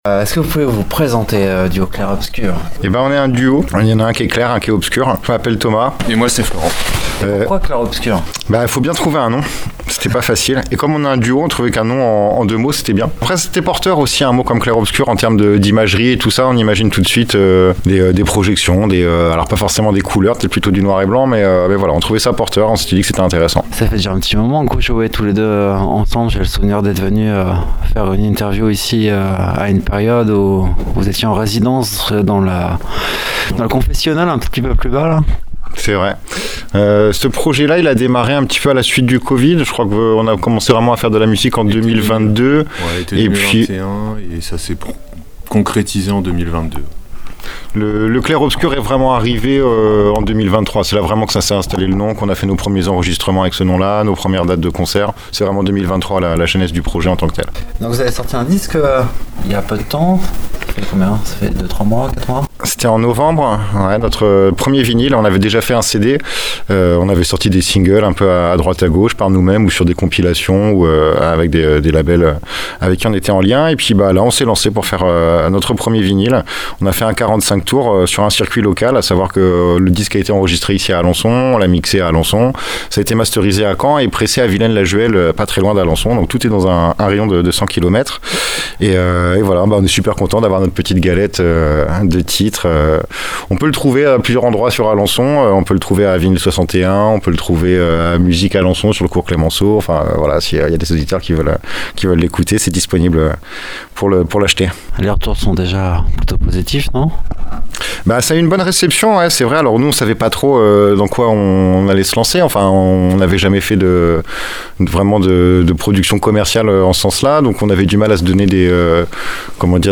Dans cette interview réalisée aux Petits Châtelets à Alençon lors du Grand Chahut, le duo Clair Obscur revient sur son univers musical et son passage sur scène à l’occasion de cet événement de soutien. Entre influences, création et énergie live, ils partagent leur vision et leur attachement à la scène locale. Un échange spontané, capté juste après leur set, au cœur d’un lieu culturel aujourd’hui menacé.